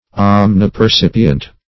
Search Result for " omnipercipient" : The Collaborative International Dictionary of English v.0.48: Omnipercipient \Om`ni*per*cip"i*ent\, a. [Omni- + percipient.] Perceiving everything.
omnipercipient.mp3